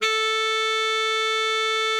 Added more instrument wavs
bari_sax_069.wav